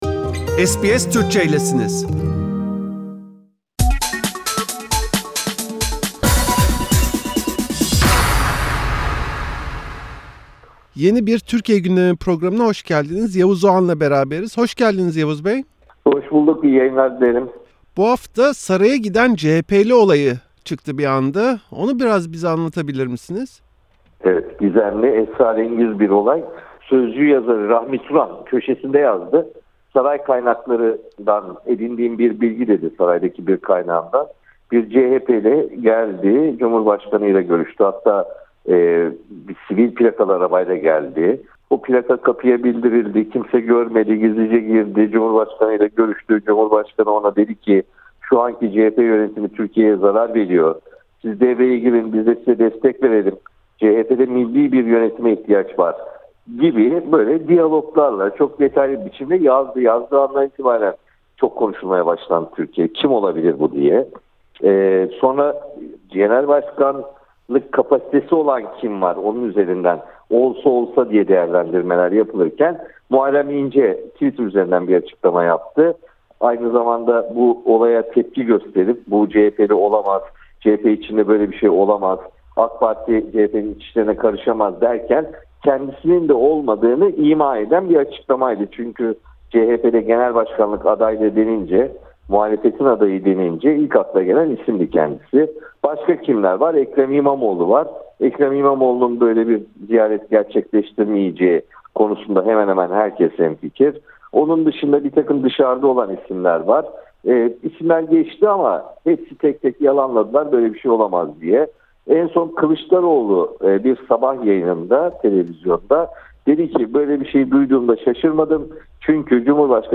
SBS Türkçe, bu haftaki Türkiye Gündemi programında gazeteci Yavuz Oğhan'la Sözcü yazarı Rahmi Turan'ın iddiasını konuştu.